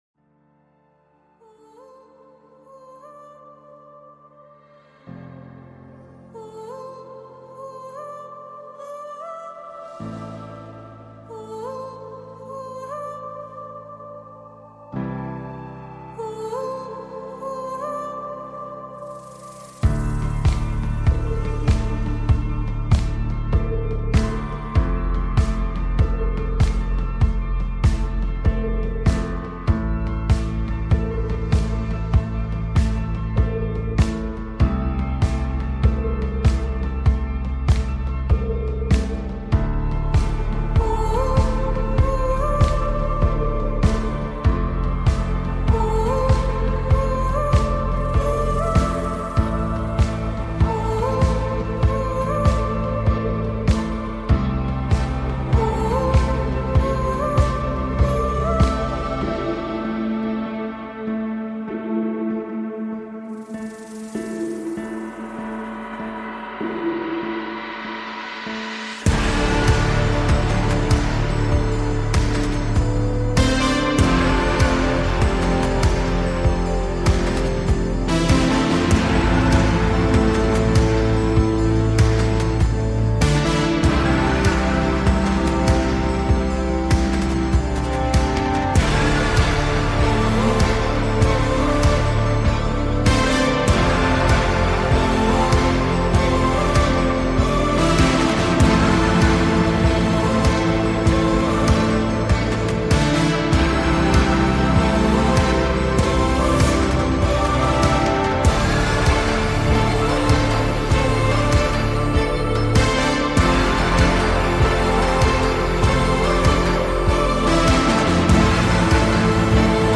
Talk Show Episode, Audio Podcast, The Beyond Earth Show and Navigating the Quickening, Subconscious Rewiring and the Art of Manifestation on , show guests , about Navigating,Subconscious Rewiring,Art of Manifestation,collective evolution,Virgo lunar eclipse,Mercury Retrograde,Spiritual Coherence, categorized as Health & Lifestyle,Alternative Health,Physics & Metaphysics,Self Help,Society and Culture,Spiritual,Access Consciousness,Psychic & Intuitive,Theory & Conspiracy